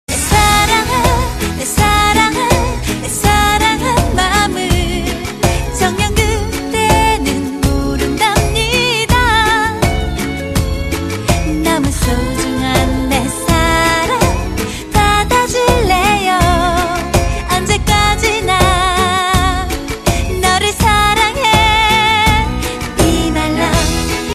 日韩歌曲